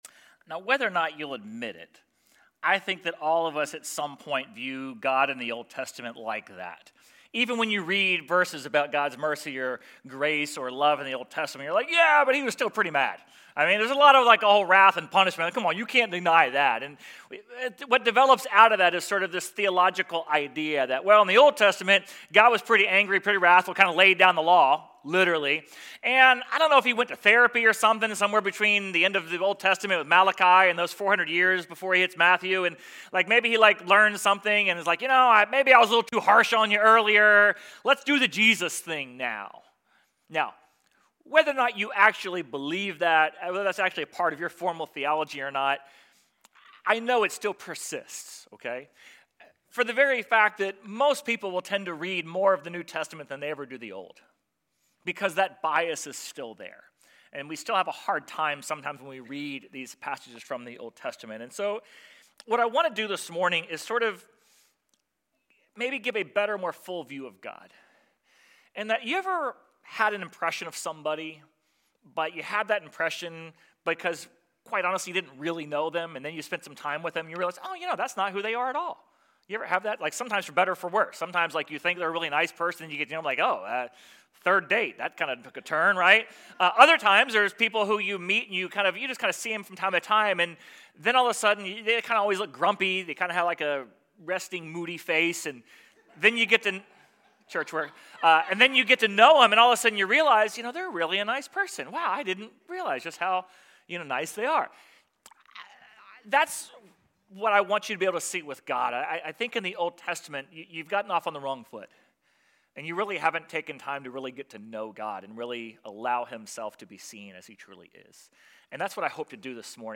Sermon_3.29.26.mp3